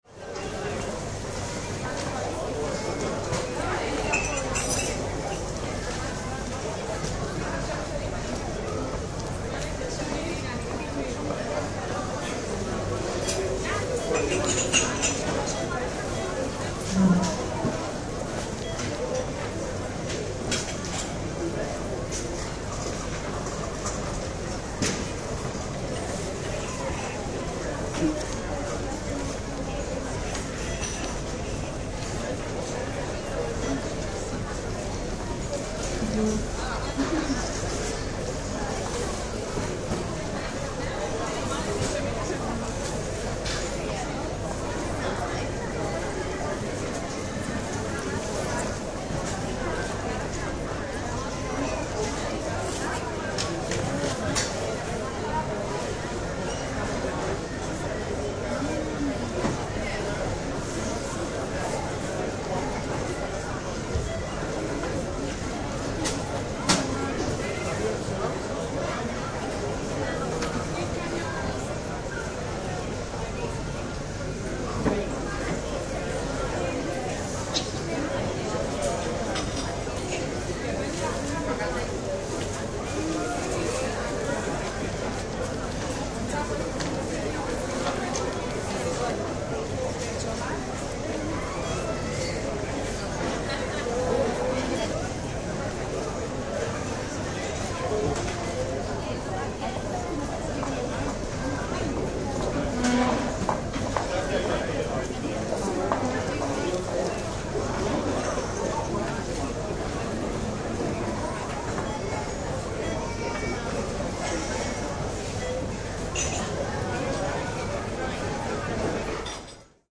Фоновые шумы посуды, разговоров и музыки помогут воссоздать атмосферу заведения или использовать их для творческих проектов.
Шум и мелодии кофейной атмосферы